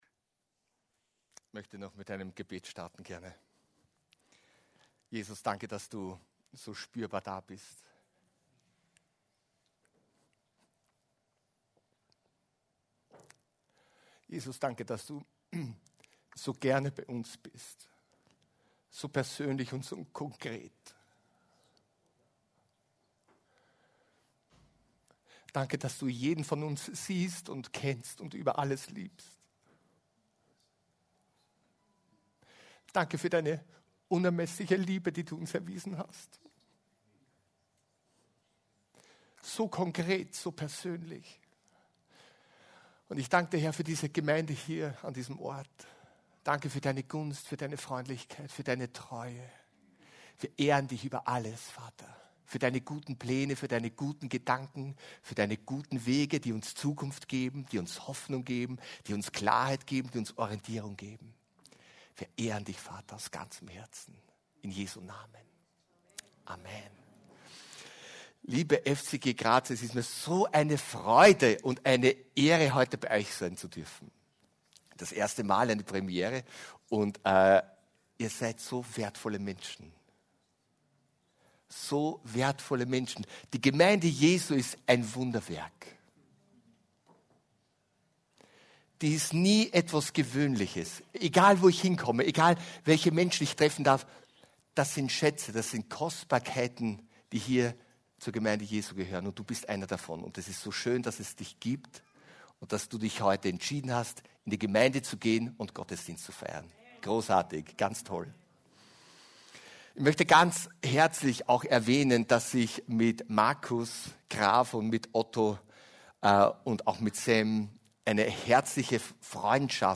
Hier finden sie die Predigten der Freien Christengemeinde - Pfingstgemeinde Graz.